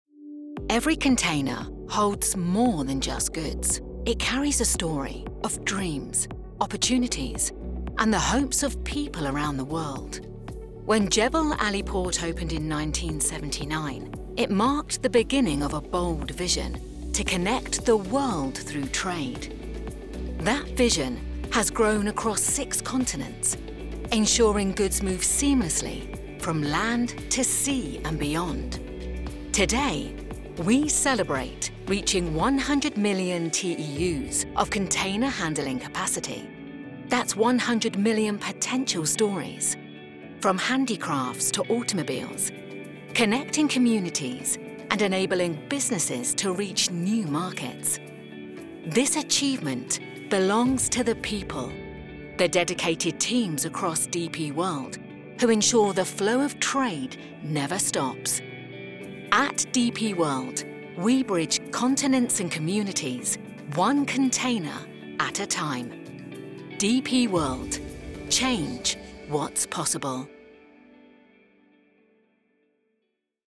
Professional vocal booth with fully wired internet for stable, uninterrupted sessions. Recording setup includes TwistedWave software, an Audient iD4 interface, and a Synco D2 shotgun microphone.
britisch
Sprechprobe: Industrie (Muttersprache):
With experience across corporate, brand, and commercial projects, I deliver clear, confident audio with a quick turnaround and zero fuss.